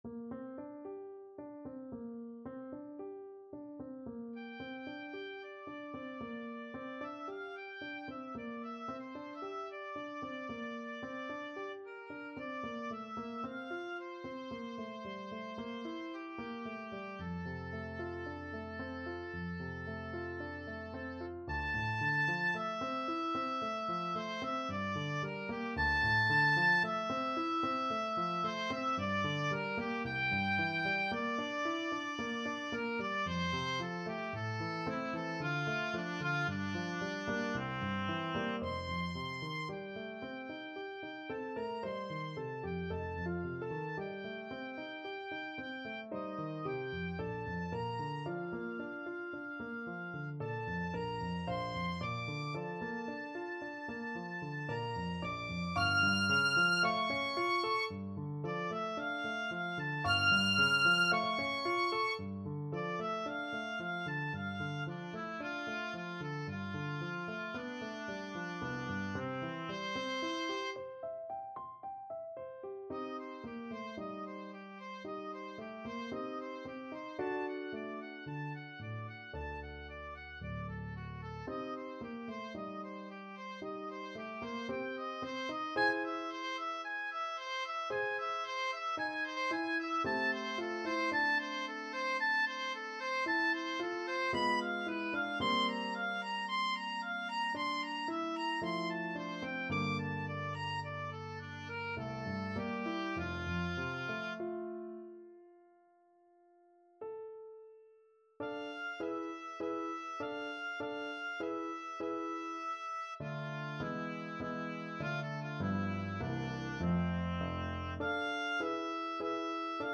OboePiano
4/4 (View more 4/4 Music)
F major (Sounding Pitch) (View more F major Music for Oboe )
Classical (View more Classical Oboe Music)